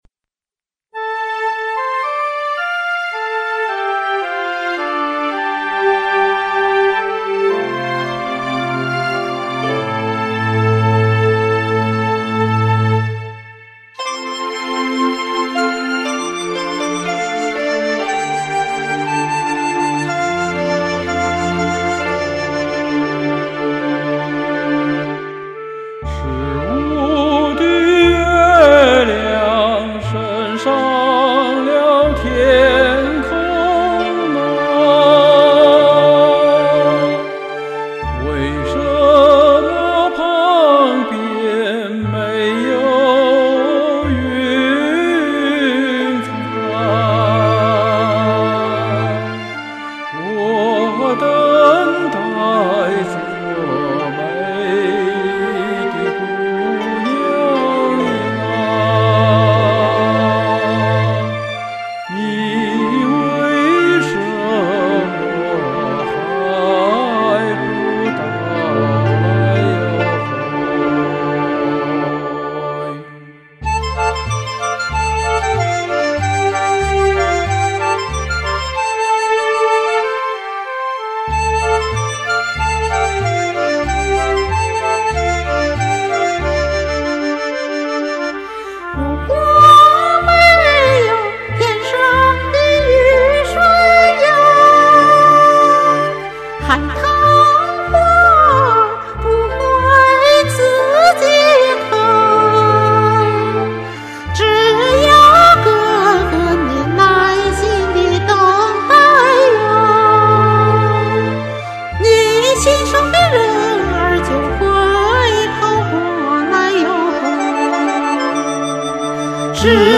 伴奏也好棒。
沉稳的大哥哥活泼的小妹妹，喜相逢的场景：）抒情优美！
很好听的男女声二重唱。。。